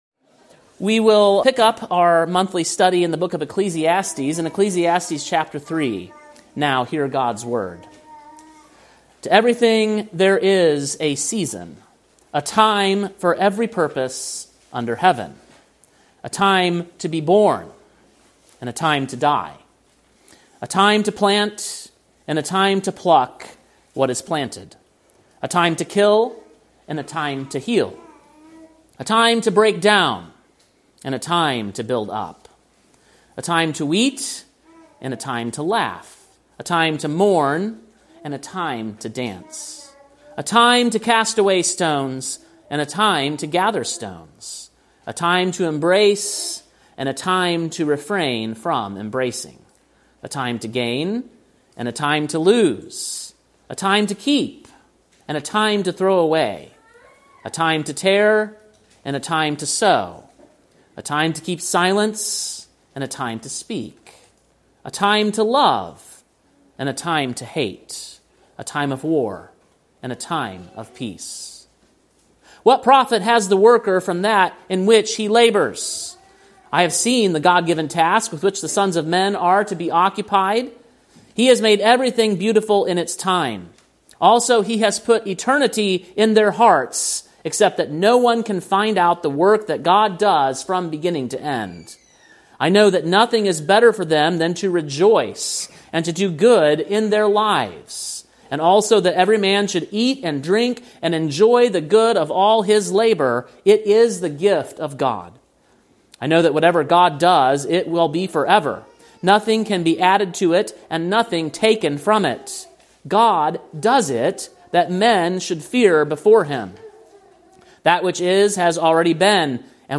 Sermon preached on December 28, 2025, at King’s Cross Reformed, Columbia, TN.